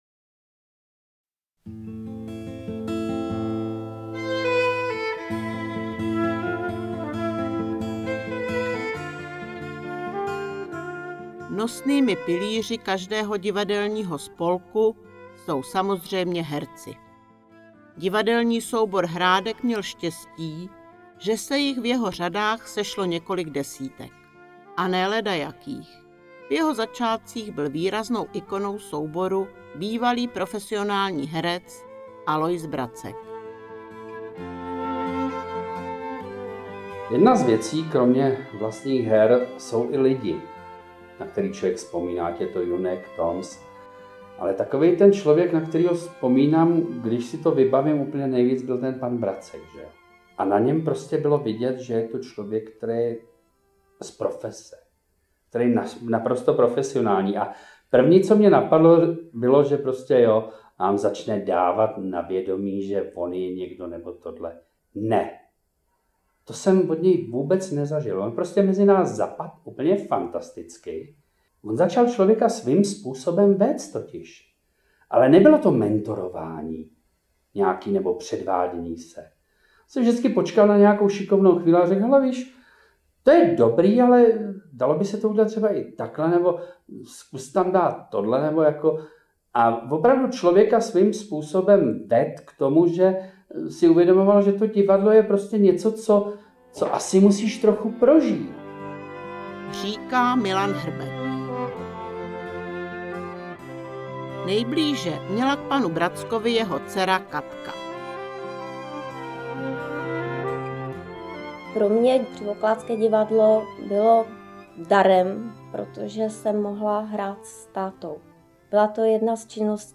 Ve stopách Thalie, Vzpomínky - bonus je audio přílohou dvoudílného filmového průvodce po historii ochotnického divadla v městysi Křivoklát. Komentované listinné a obrazové dokumenty, vzpomínky křivoklátských ochotníků.